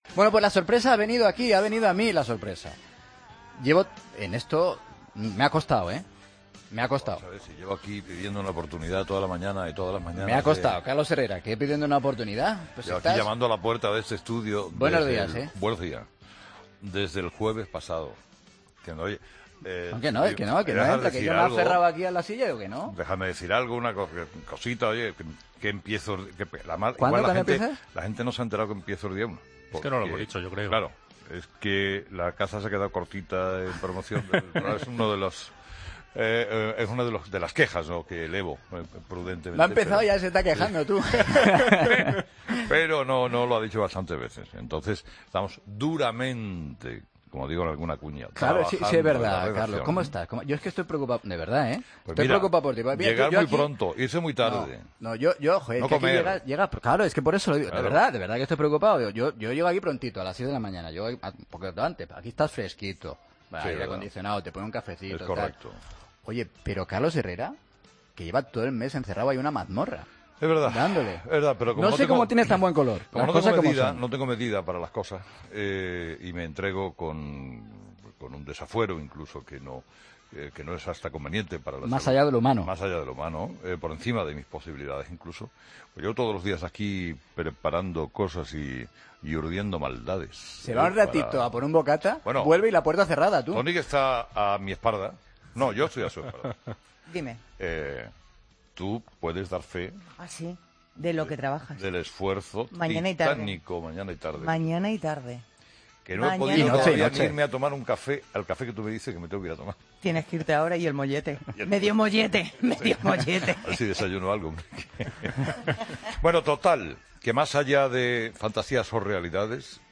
A escasos días de que comience su nueva etapa en COPE, Carlos Herrera nos visita en La Mañana con un pequeño adelanto de la nueva temporada.